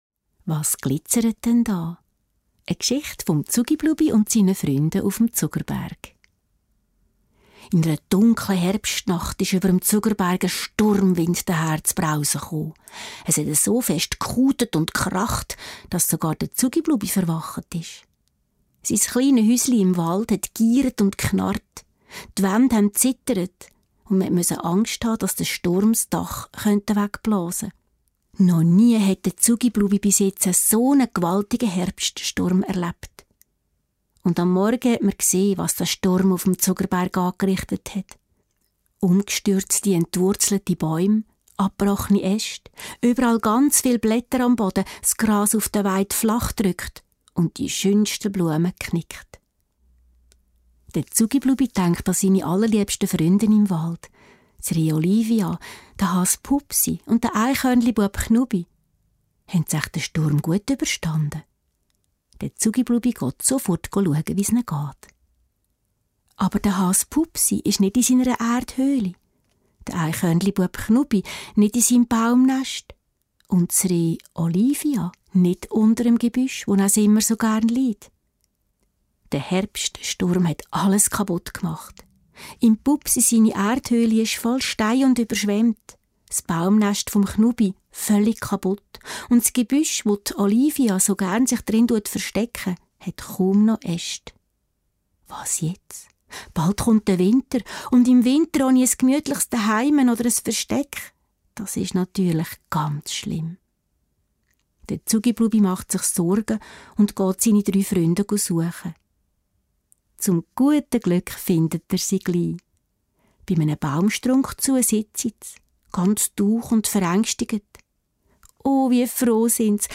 - Geschichte lesen - Geschichte hören